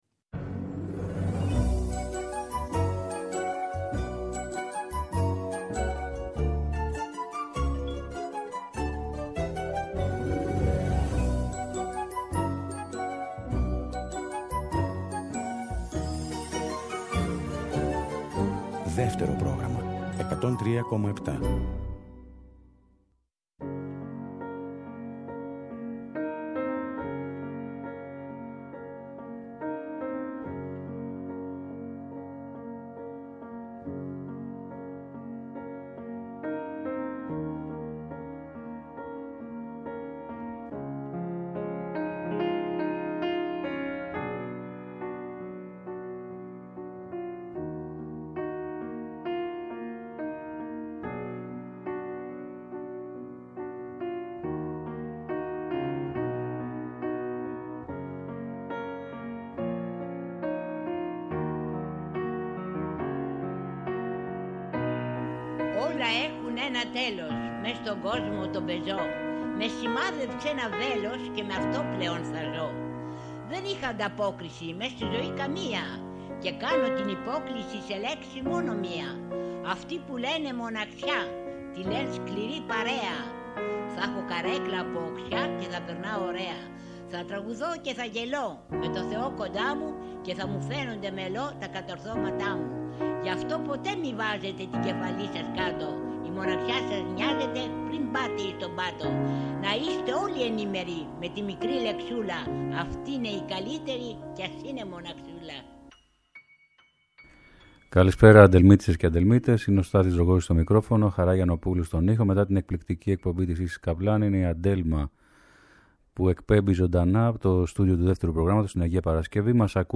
ελληνικό χιπ χοπ
Τραγούδια ωμά, ενοχλητικά και αληθινά